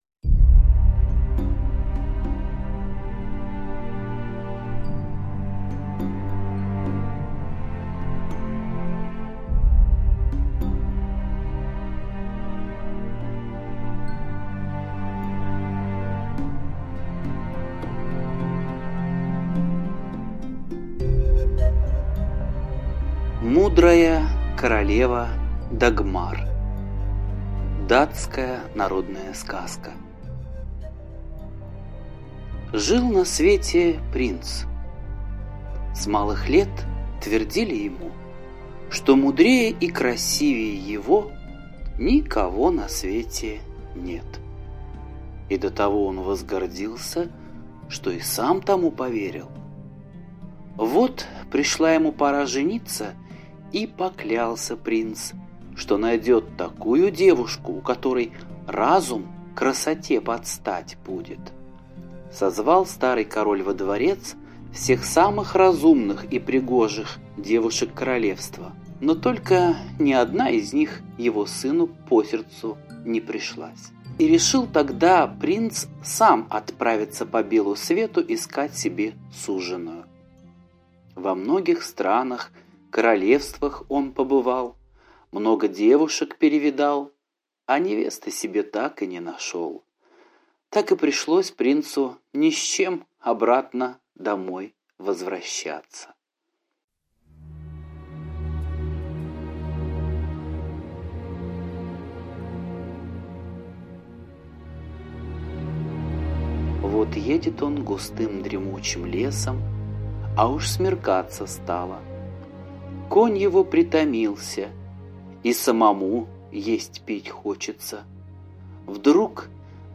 Слушайте Мудрая королева Дагмар - датская аудиосказка. Про принца, который искал себе в жены умную и красивую девушку.